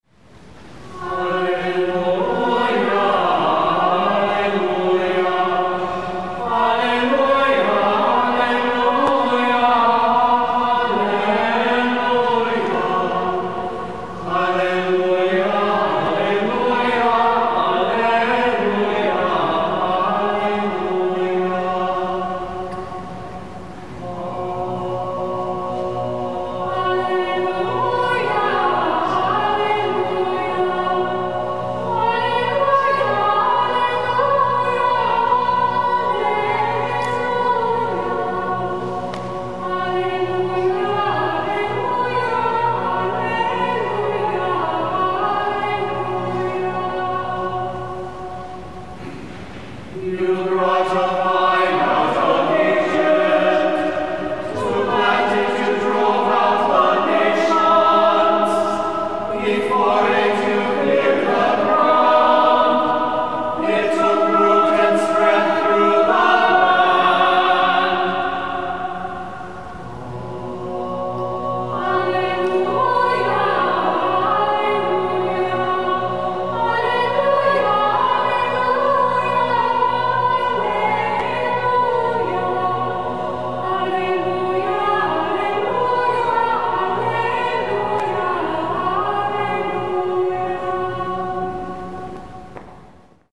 Here are audio clips of some Gregorian chant (excerpts in each case) from the abbey, last Sunday’s Fifth Sunday of Easter, with some musings.
ninefold_alleluia from Easter season Lauds in the new Antiphonale Monasticum, with verses in English to a Meinrad tone. Pedal point on antiphon, simply harmonization on verses.